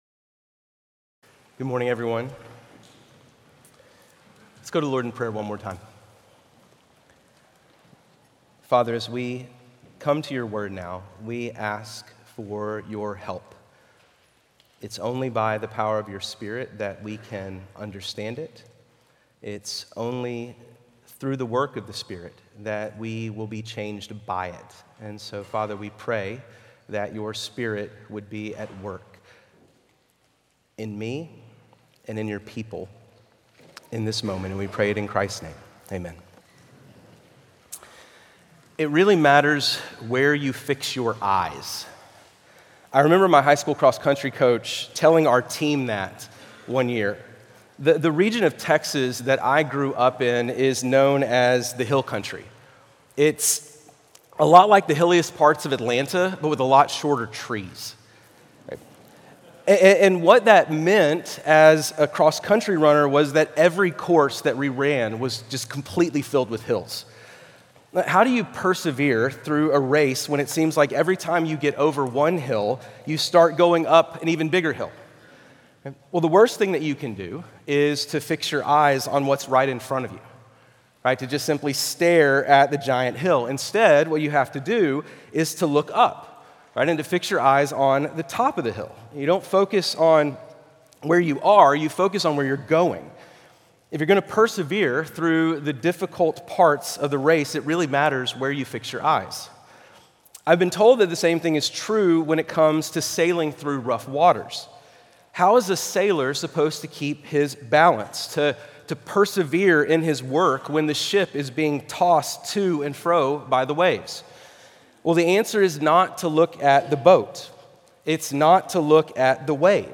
Guest Preachers